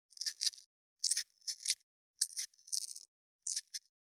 498桂むき,大根の桂むきの音切る,包丁,厨房,台所,野菜切る,咀嚼音,ナイフ,調理音,
効果音厨房/台所/レストラン/kitchen食材